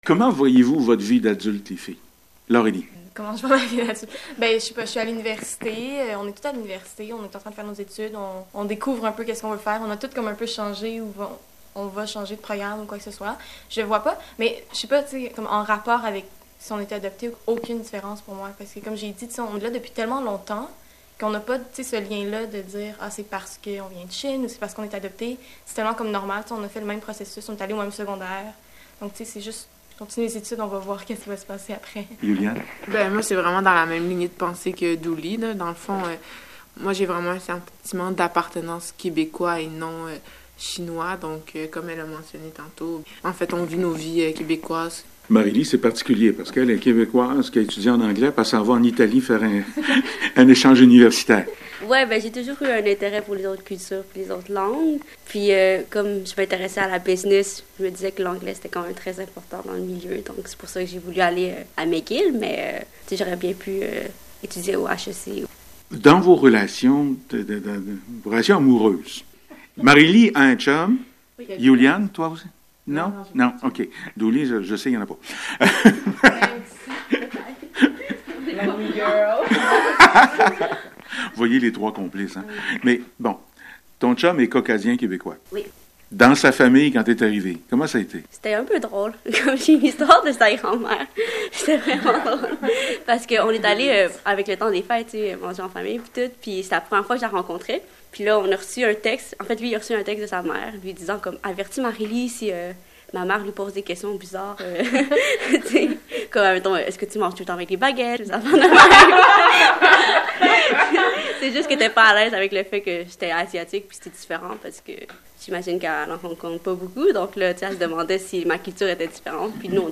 émaillée de musique, enregistrée un dimanche matin d’hiver au coin d’une table de salle à dîner